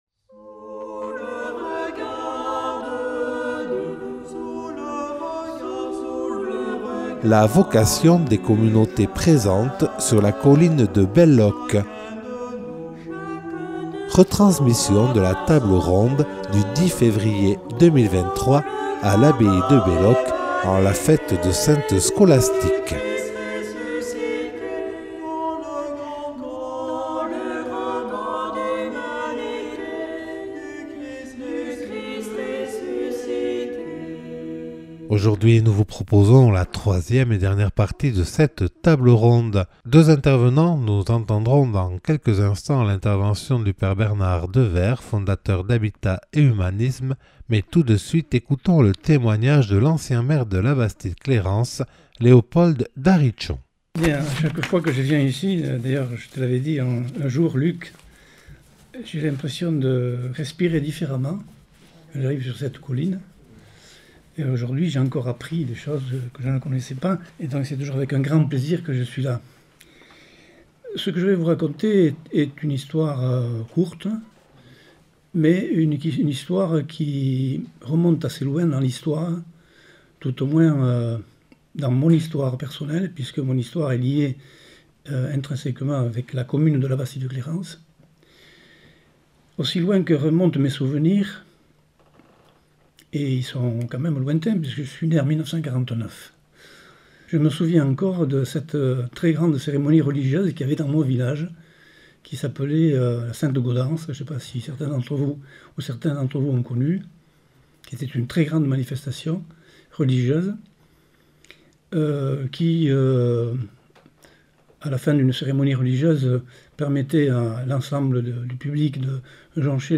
Retransmission de la table ronde du 10 février 2023 à l’abbaye de Belloc